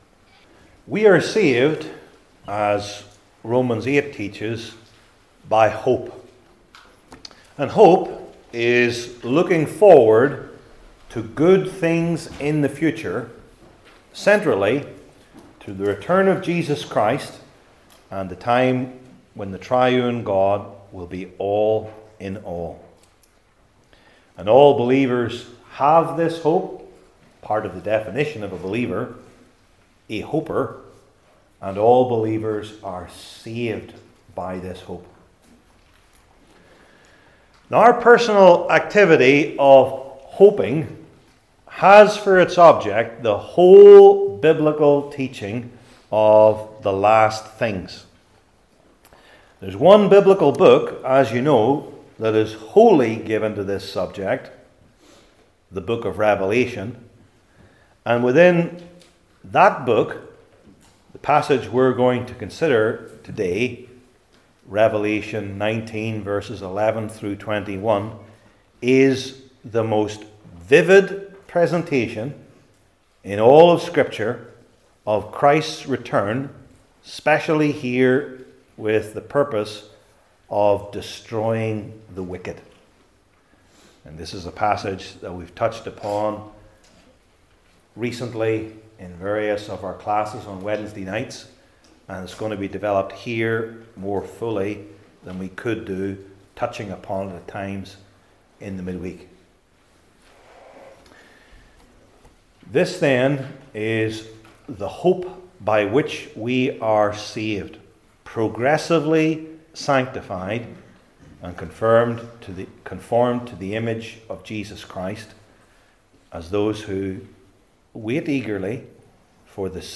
New Testament Individual Sermons I. The Glorious Vision II.